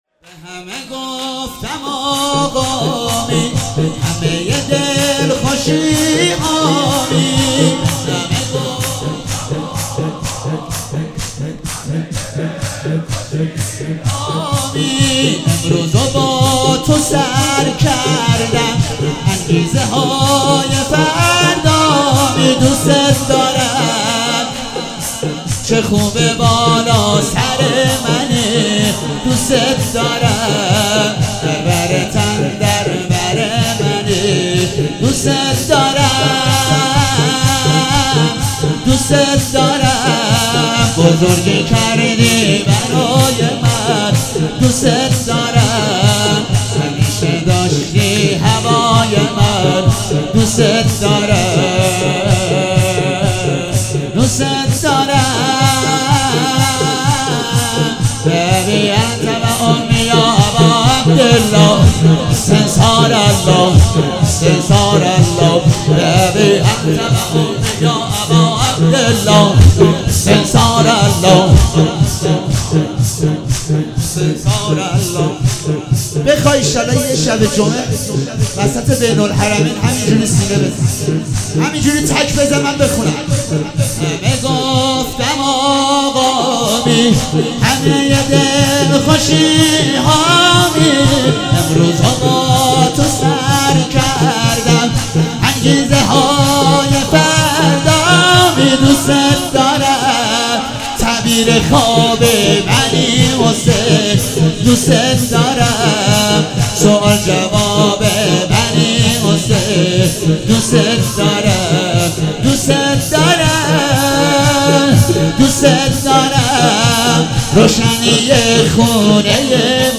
شور - به همه گفتم آقامی